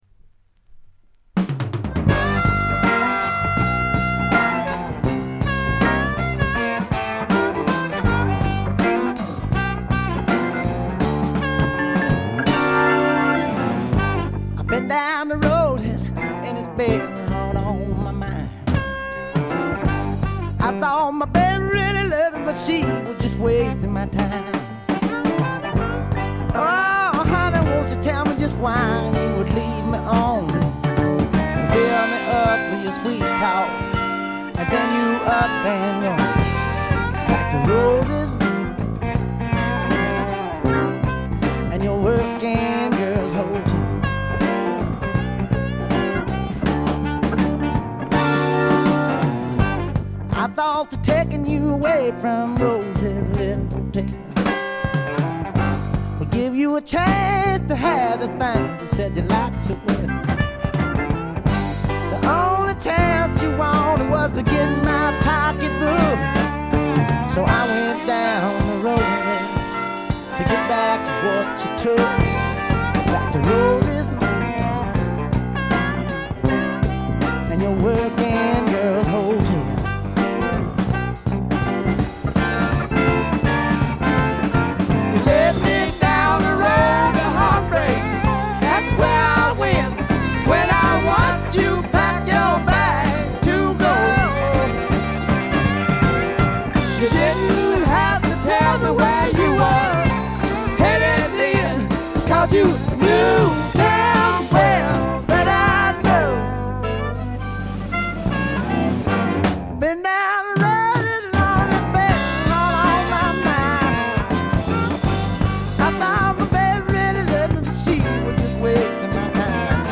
静音部での微細なバックグラウンドノイズ、散発的なプツ音軽いものが2回ほど。
びしっと決まった硬派なスワンプ・ロックを聴かせます。
試聴曲は現品からの取り込み音源です。
Recorded at Paramount Recording Studio.